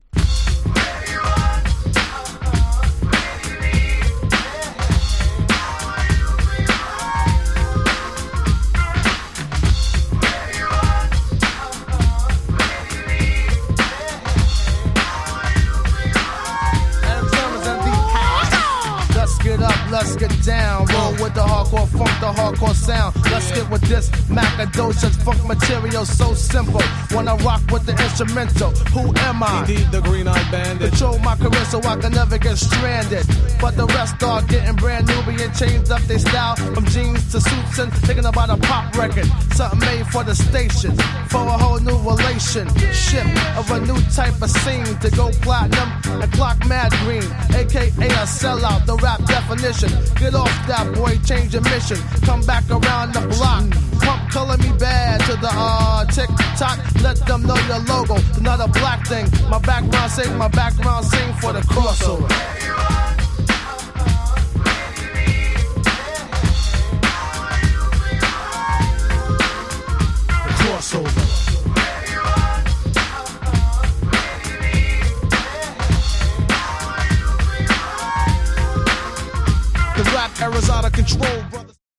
92' & 90' Super Hip Hop Classics !!